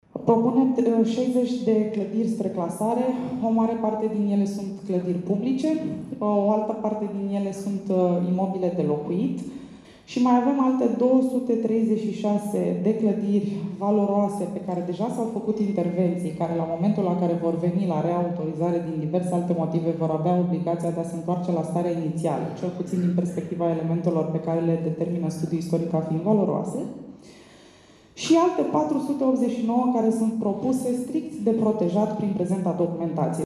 Sunt doar două din întrebările ridicate de participanții la dezbaterea publică a Planului Urbanistic Zonal al zonei centrale a municipiului Constanța.